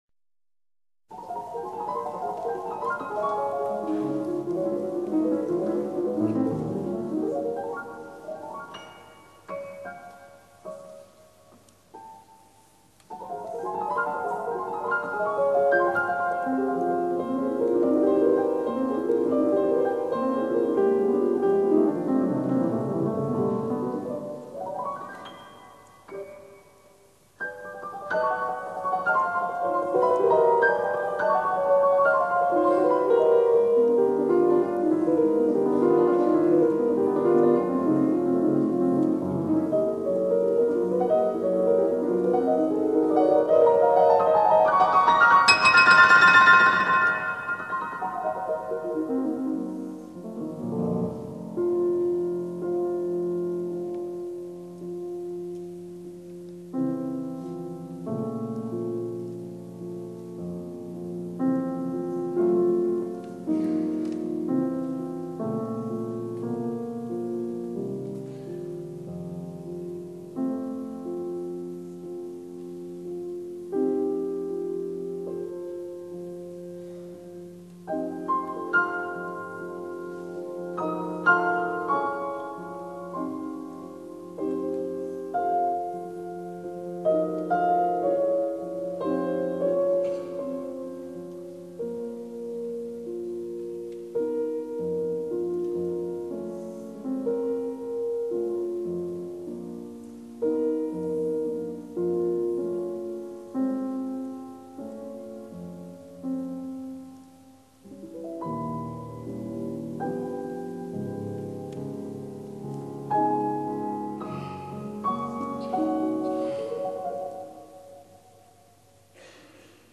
前苏联钢琴家。